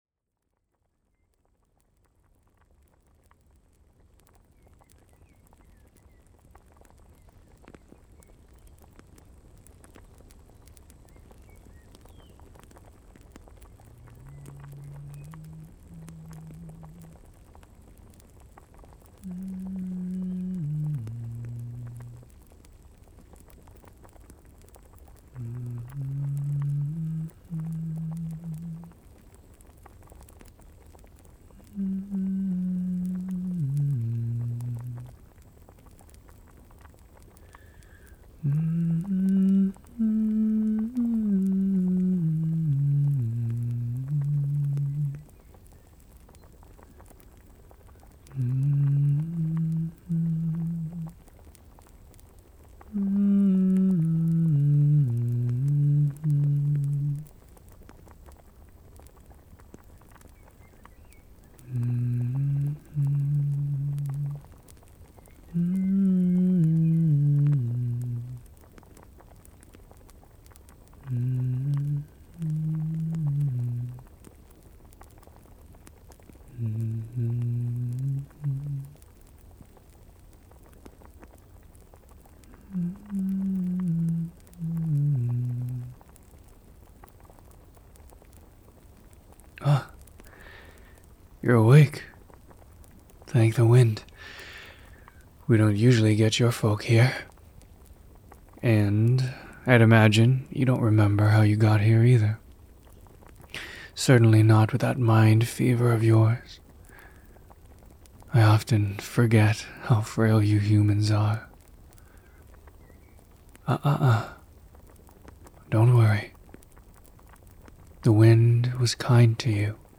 It's also been a little bit since I've done a fantasy audio, let alone a SFW fantasy roleplay.
I come before you today as a humble big headed man pretending to be an elf with this audio. If you're a fan of repeated comforting words, then I think you'll like this one! Or if you just like the idea of some fae being taking care of you, a poor, unfortunate human, then I think you'll like this one!